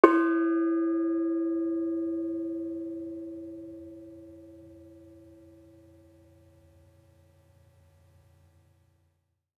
Bonang-E3-f.wav